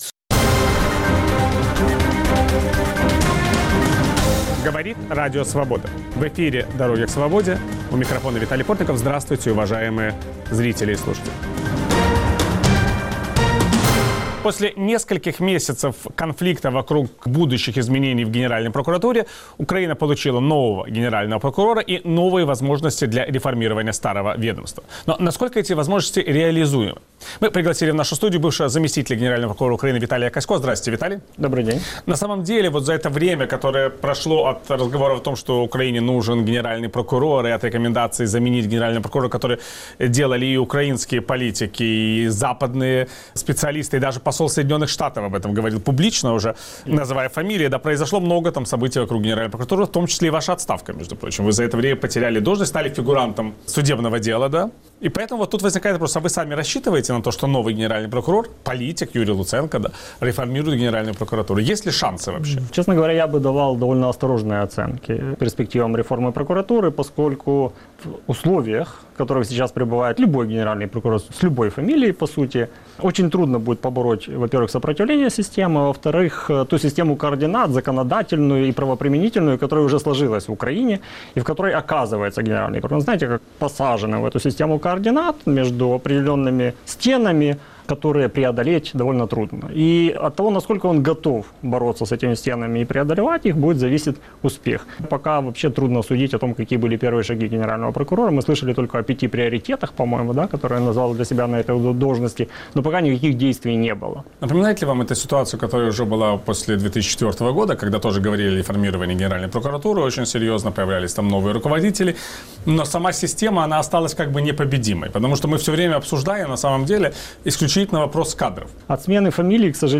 Украинский парламент утвердил нового генерального прокурора страны. Какие вызовы стоят перед прокуратурой и обществом? Собеседник Виталия Портникова - бывший заместитель генерального прокурора Украины Виталий Касько